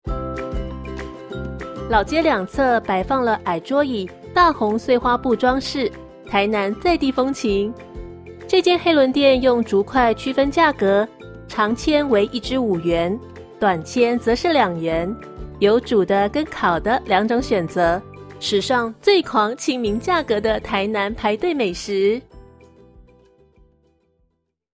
中文語音解說